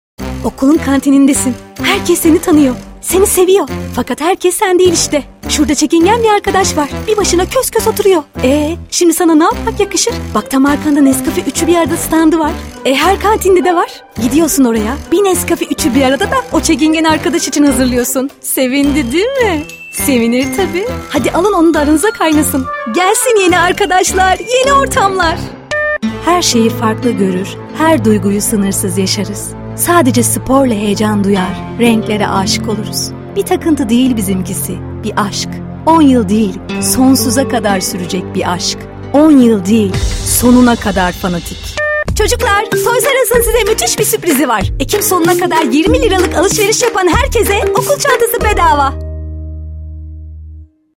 Reklam Demo
Profesyonel kadın seslendirmen. Reklam, tanıtım ve kurumsal projeler için ideal ses tonu.